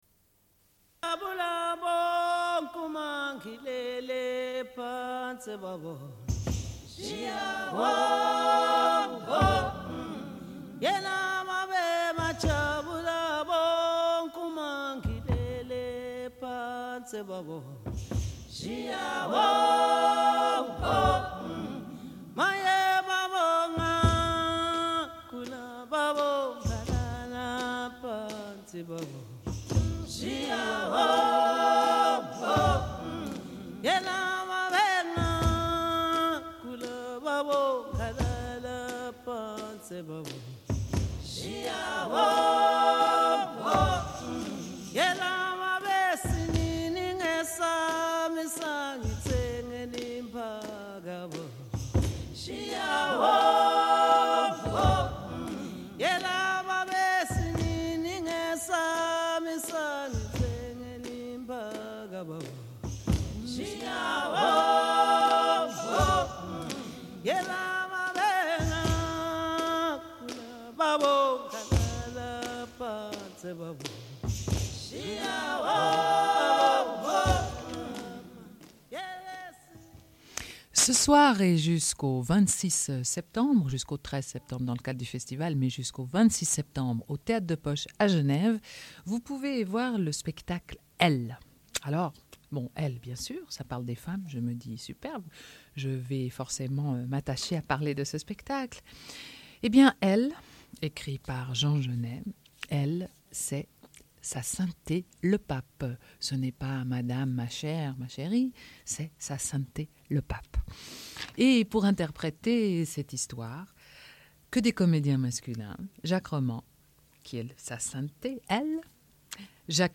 Une cassette audio, face A31:47